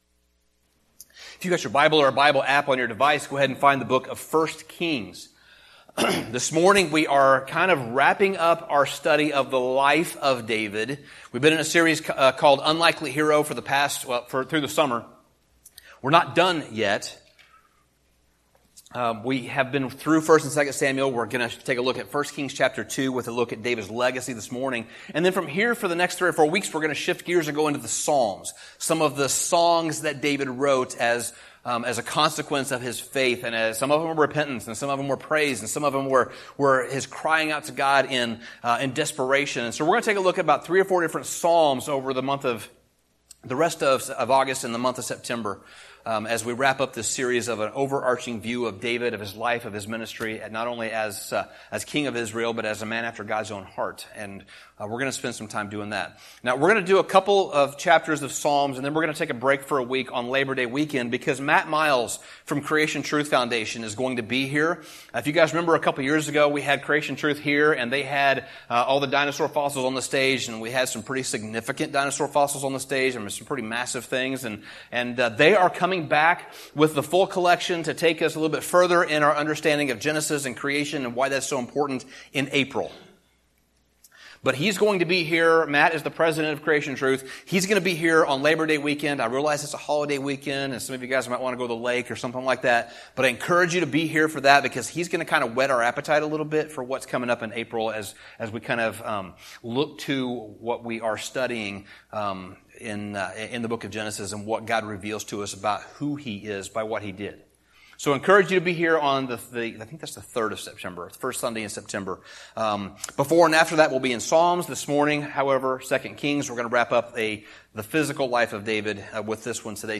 Sermon Summary At the end of David's life, he calls his son, Solomon, and gives him some input and advice about how to rule the nation of Israel well as the next king.